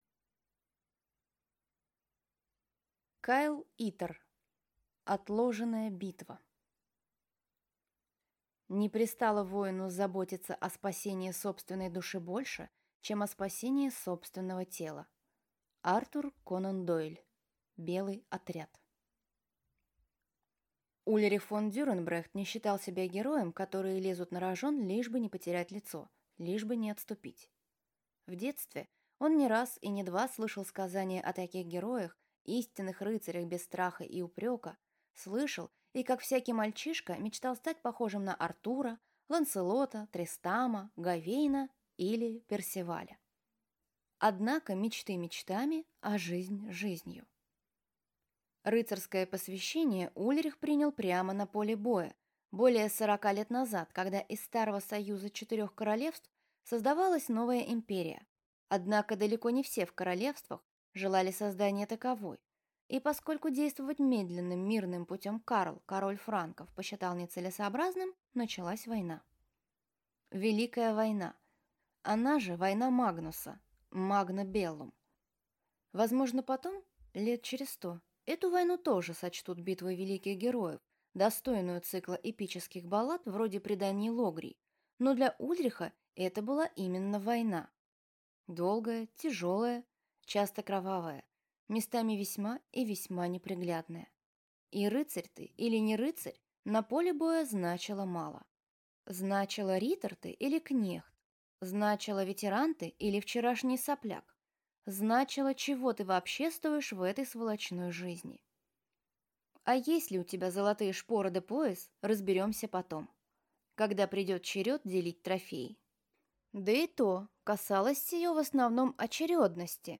Аудиокнига Отложенная битва | Библиотека аудиокниг